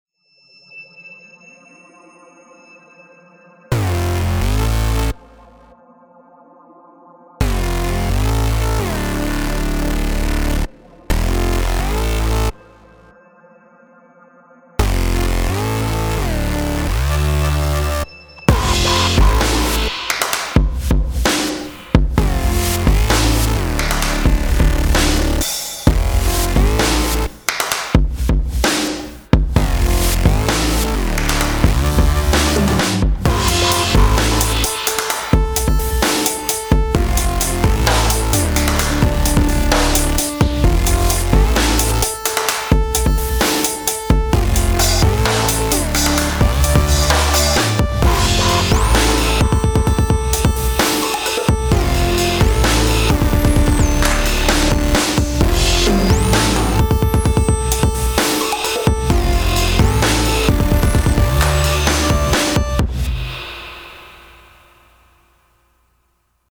electronic song with aggressive vibes
Subsonic synthesizer is the driving force behind
130 BPM
electronic aggressive synthesizer drums